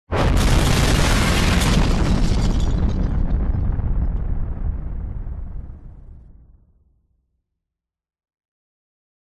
На этой странице собраны звуки, которые ассоциируются с концом света: от далеких взрывов и гула сирен до хаотичного шума разрушающегося мира.
Грохот взрыва Земли в момент апокалипсиса